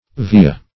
Uvea - definition of Uvea - synonyms, pronunciation, spelling from Free Dictionary
Uvea \U"ve*a\, n. [NL., fr. L. uva grape.] (Anat.)